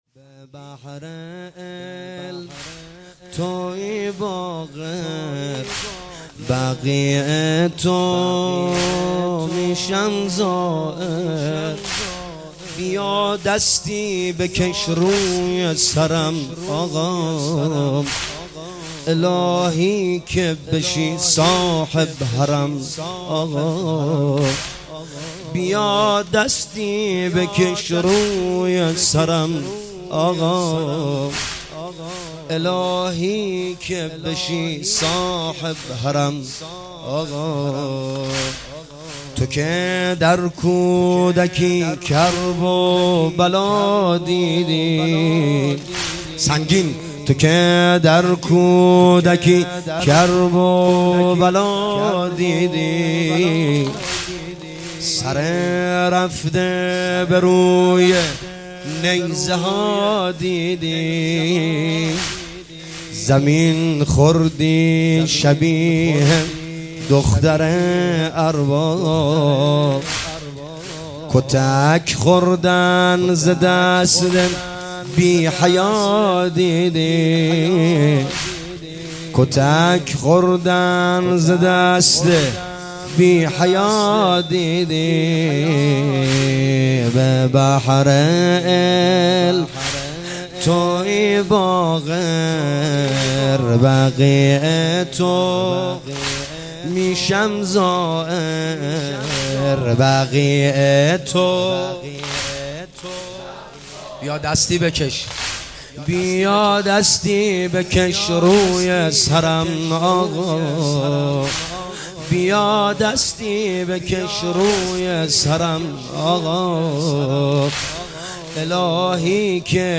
مداحی
با نوای دلنشین